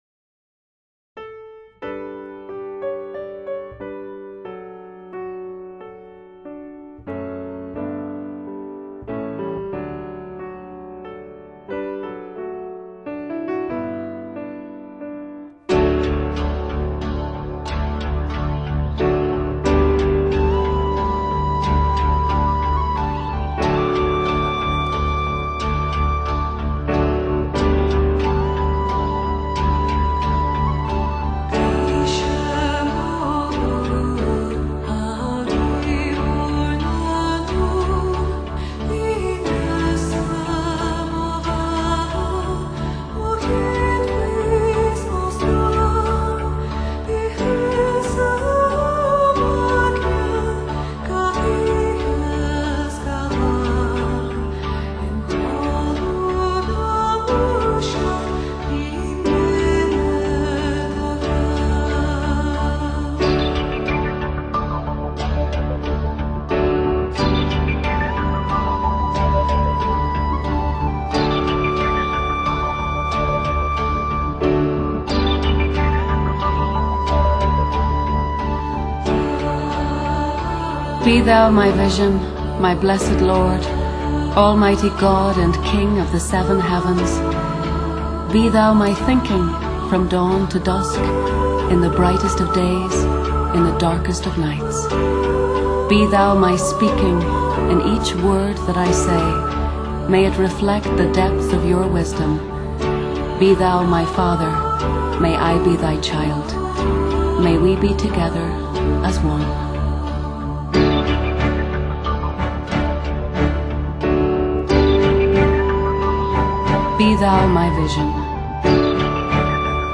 This serene, uplifting collection